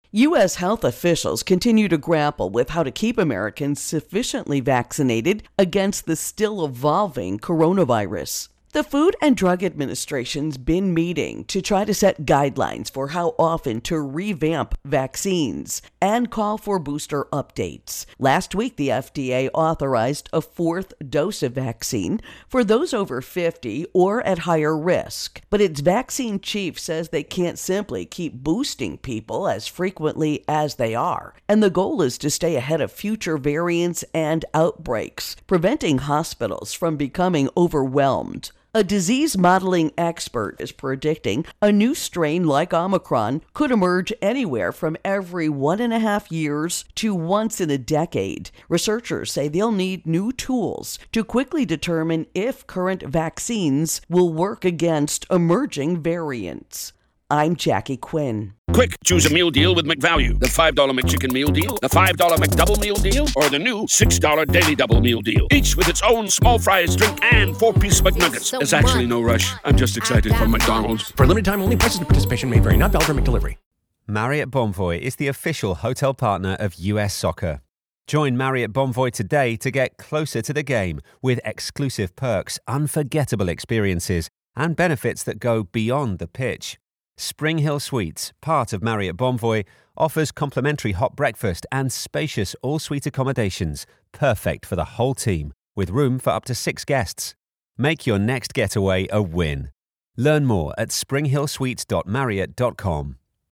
MED Virus Outbreak Vaccines Intro and Voicer